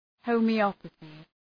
Προφορά
{,həʋmı’ɒpəɵı}